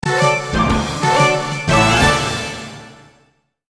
Golf_Hole_In_One.ogg